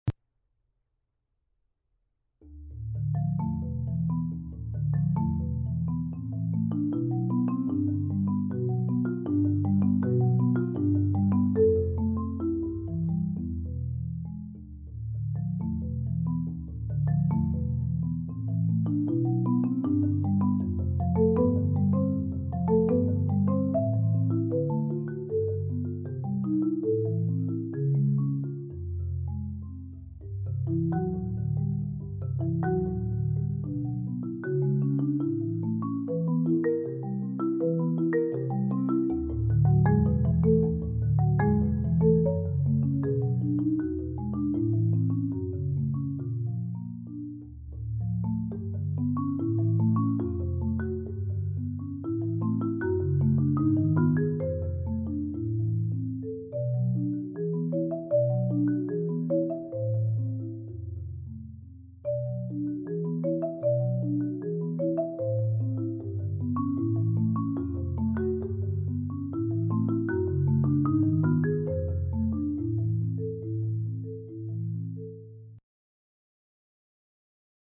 Genre: Marimba (4-mallet)
A charming and expressive solo for marimba
Marimba (5-octave)